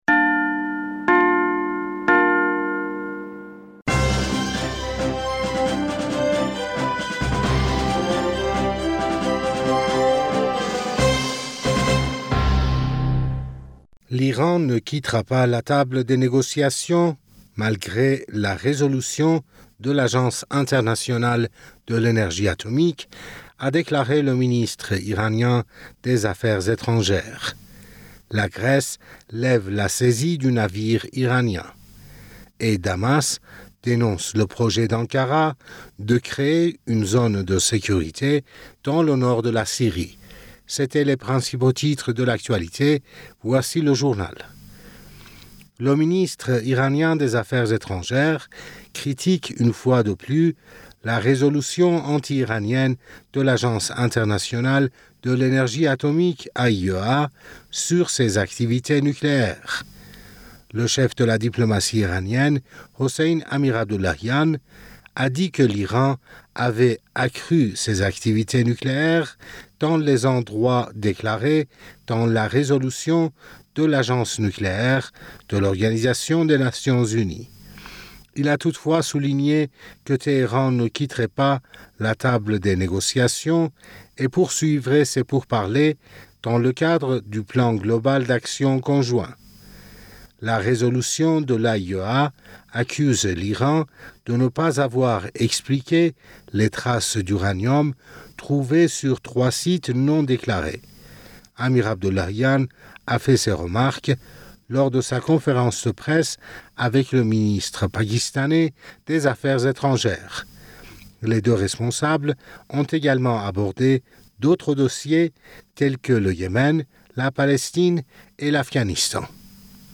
Bulletin d'information Du 15 Juin